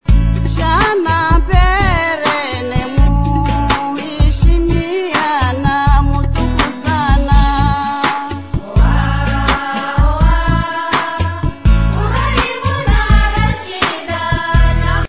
world music influences Comores